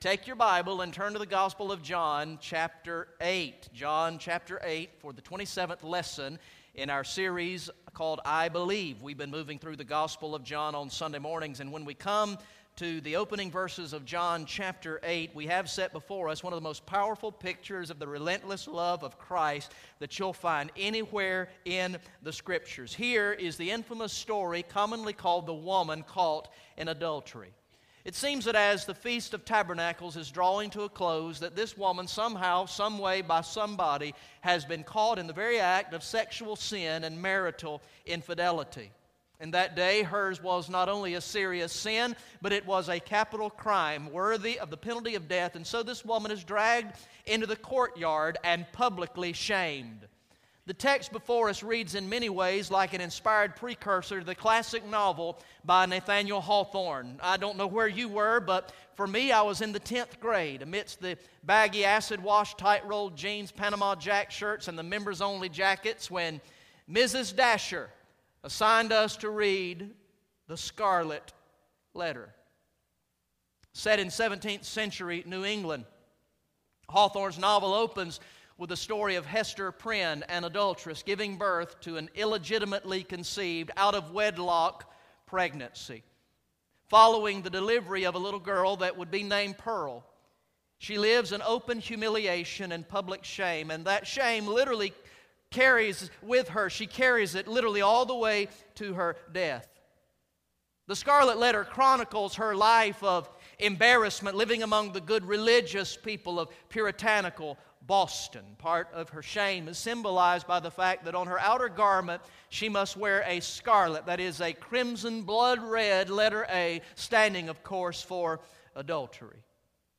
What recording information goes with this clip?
Message #27 from the sermon series through the gospel of John entitled "I Believe" Recorded in the morning worship service on Sunday, November 16, 2014